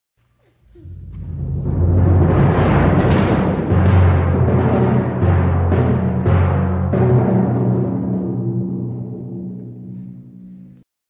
• Simfoniskā pasaka "Pēterītis un vilks" (SR) Saklausa mūzikas instrumentiem atbilstošus tēlus